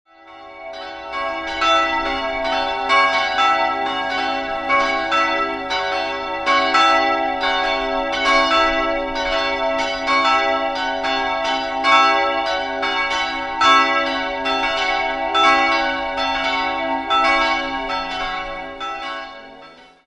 3-stimmiges TeDeum-Geläute: cis''-e''-fis''